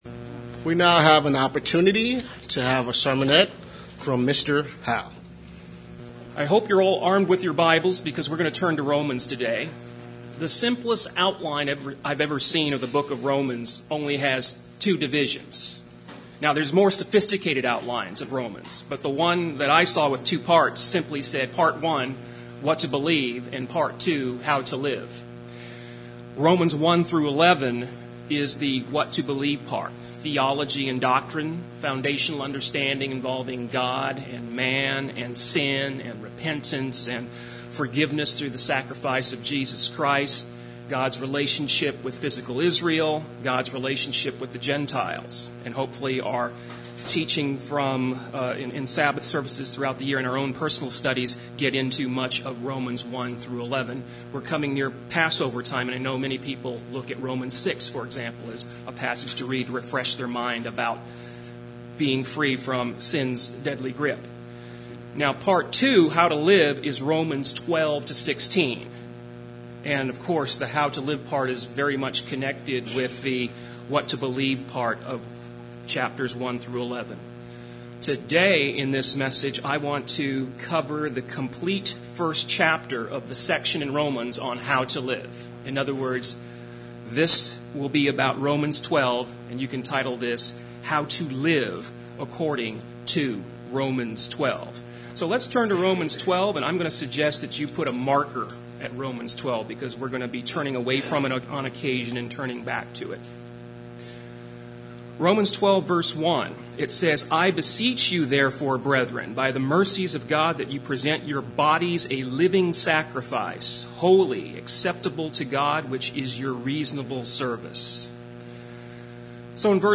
Sermons
Given in Redlands, CA